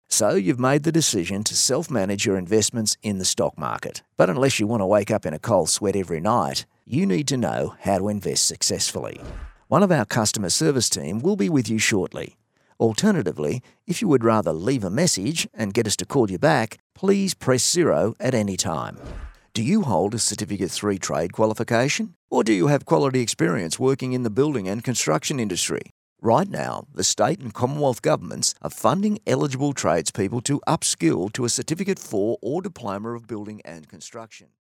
But pretty much specialises in the laid back Aussie style, relaxed , real and believable!
• On Hold
• Natural Aussie Bloke